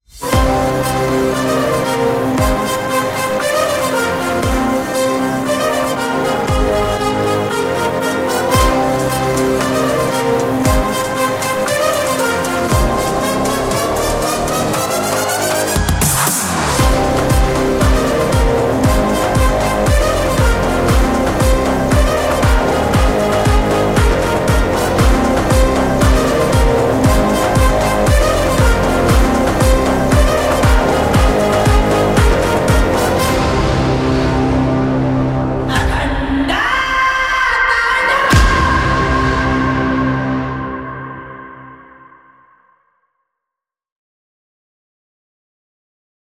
extended it like an official one with proper sound balance.